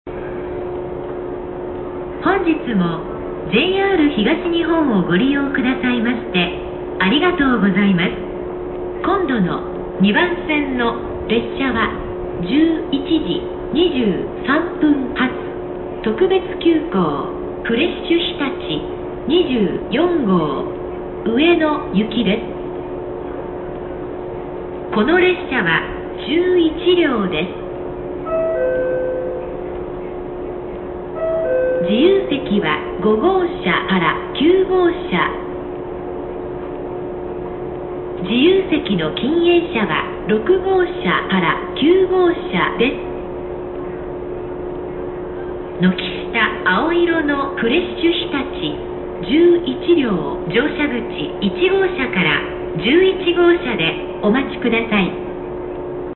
次発放送特急フレッシュひたち24号上野行き11両
特急フレッシュひたち24号の次発放送です。
最初の言い回しが特徴です。